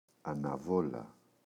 αναβόλα, η [anaꞋvola] – ΔΠΗ